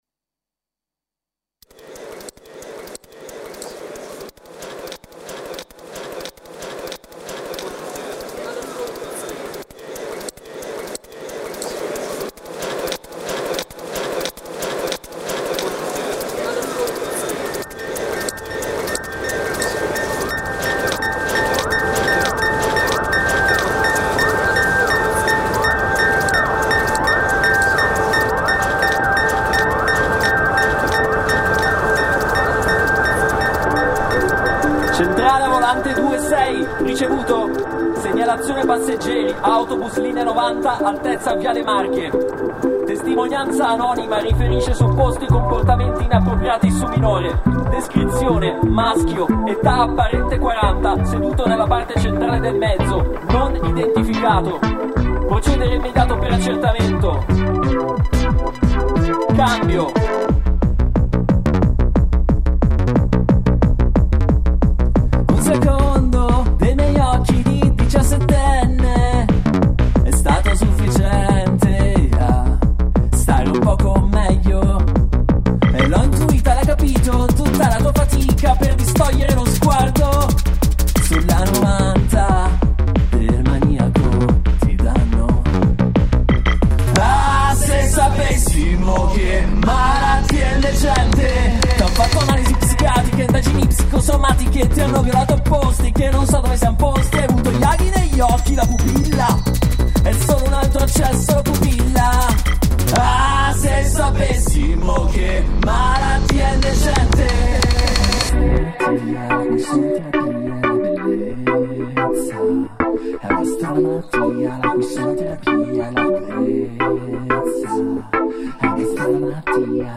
Live Pop - Cospirazione in cassa dritta - 19/02/2026